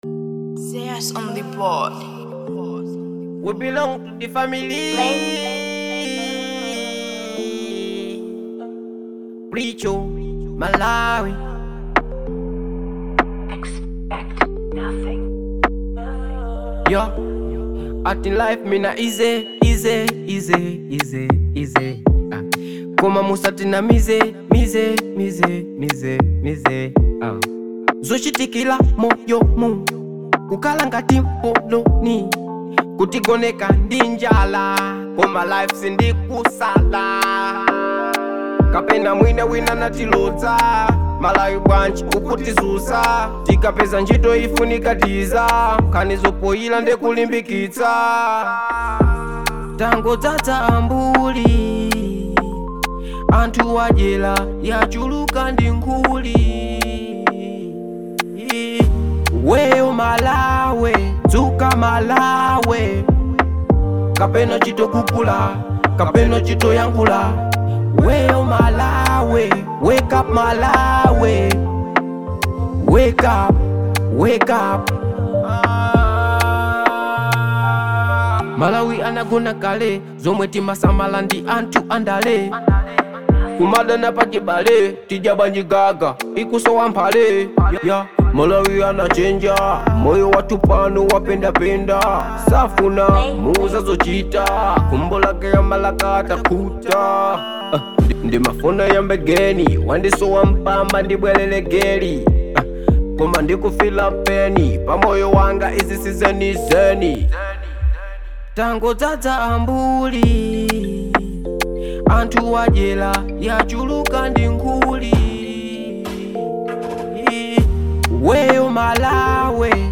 Genre : Dancehall
catchy hooks